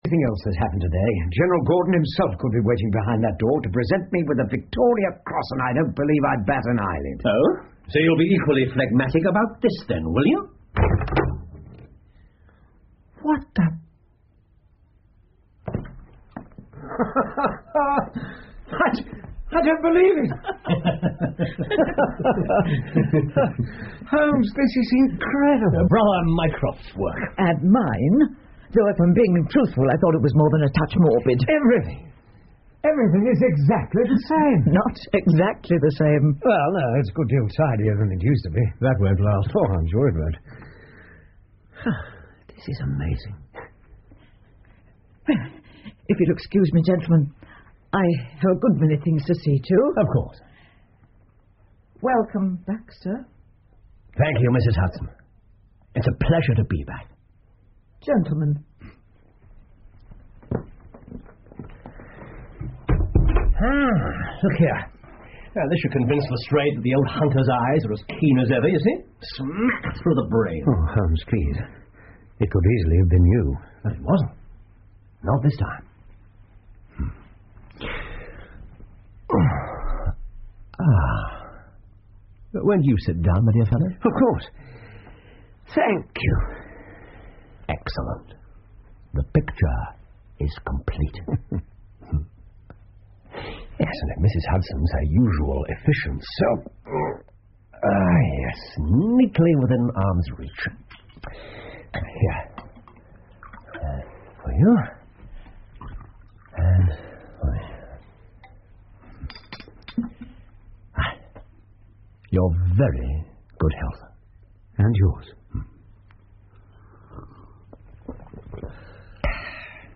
福尔摩斯广播剧 The Empty House 9 听力文件下载—在线英语听力室